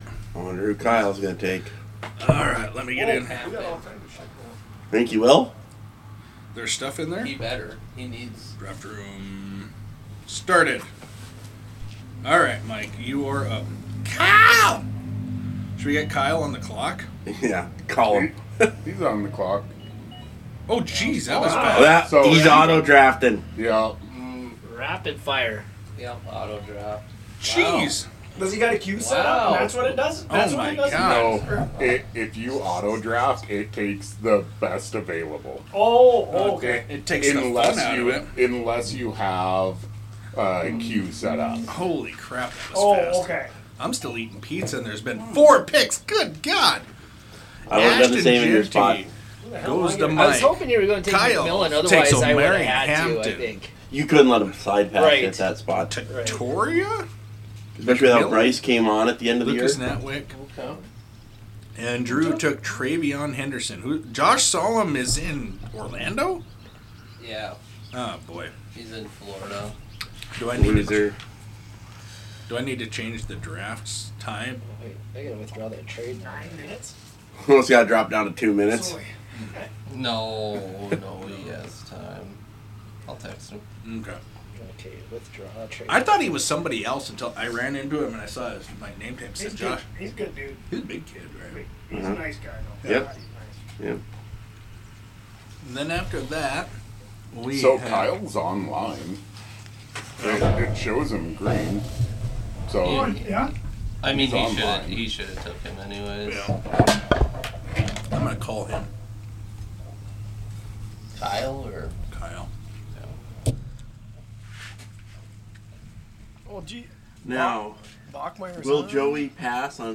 Live audio from our rookie draft. Not great sound, there are swears, and it abruptly ends because my battery died, BUT the fans are demand more content, so here ya go!